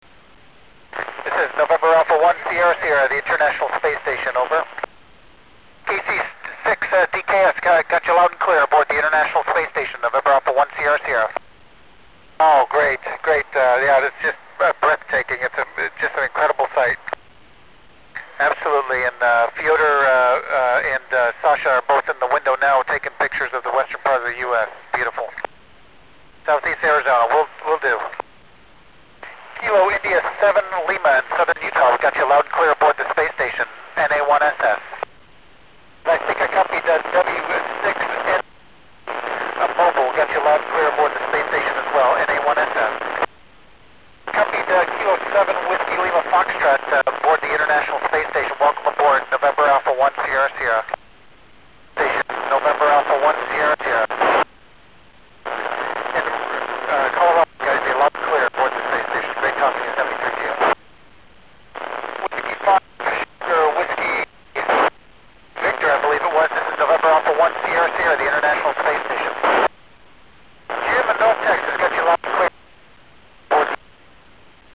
Sept 05 2230 Pass West Coast